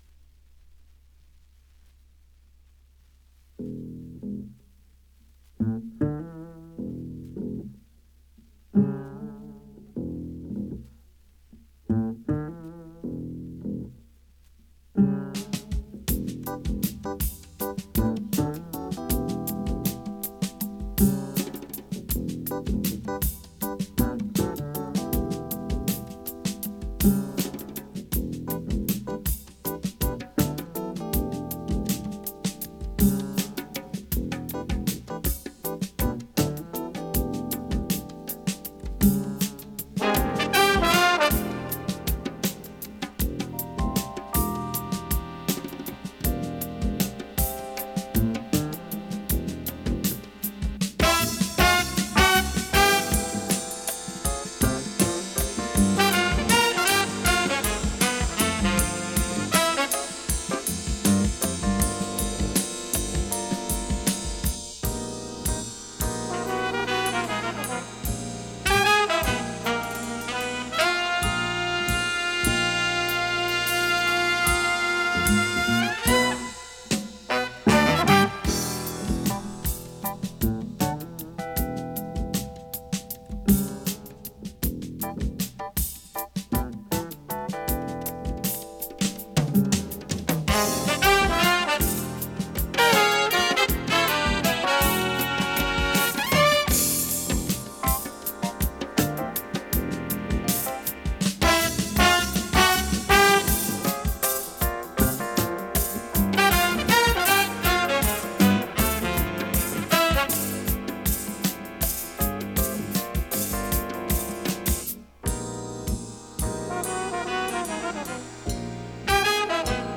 (Jazz)